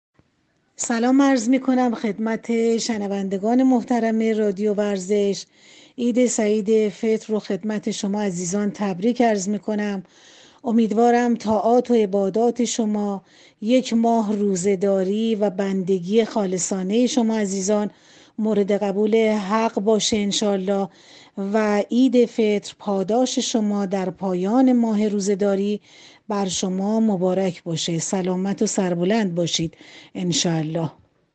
پیام تبریك معاون توسعه ورزش بانوان به مناسبت عید فطر